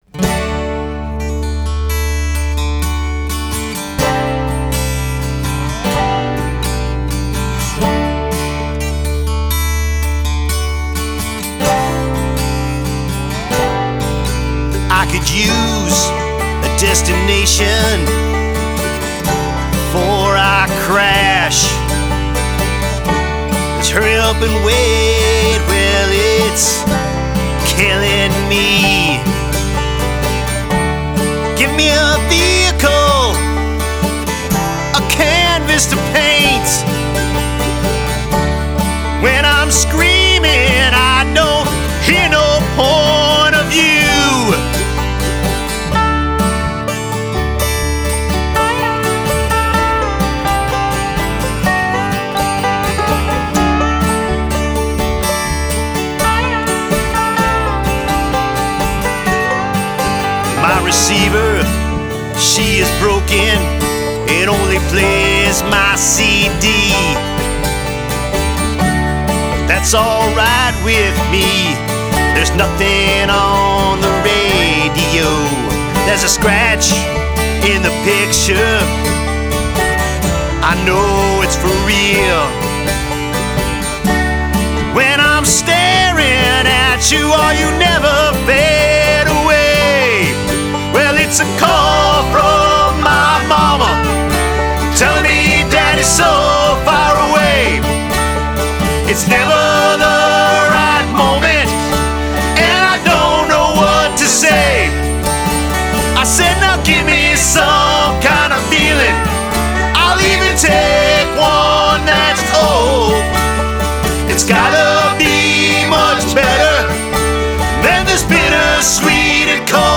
Genre: Americana.